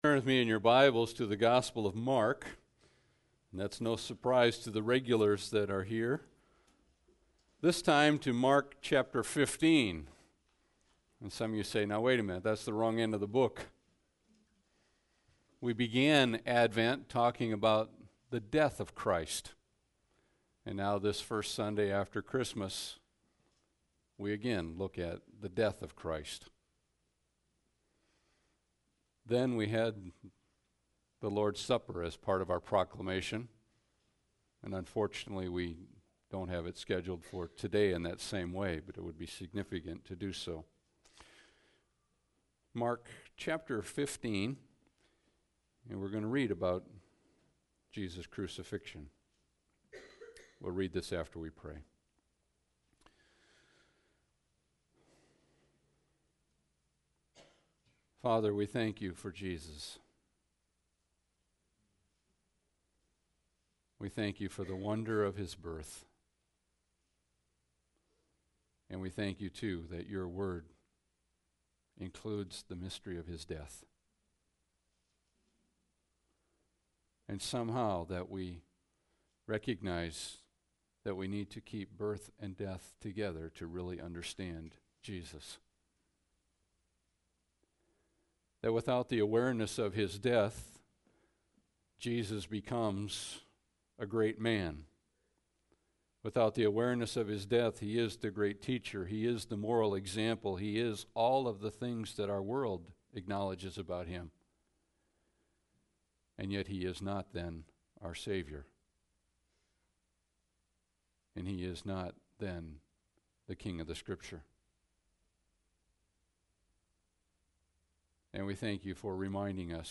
Service Type: Sunday Service